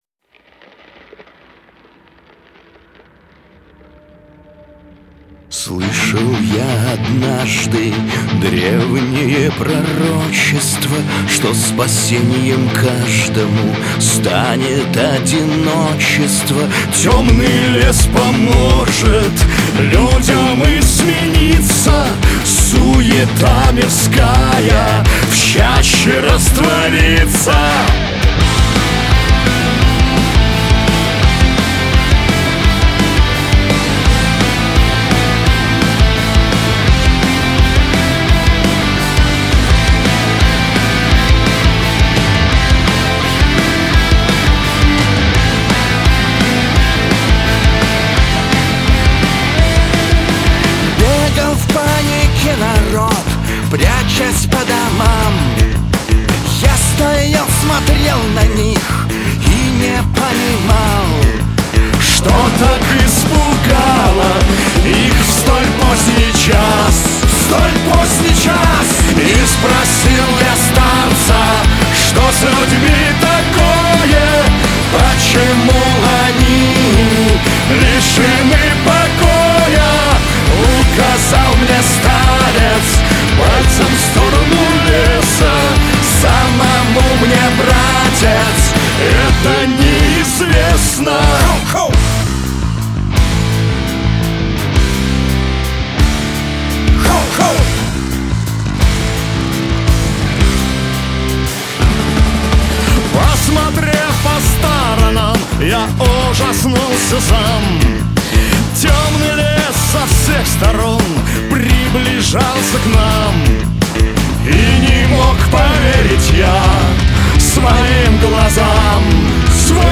Саундтреки, 2026, Русские треки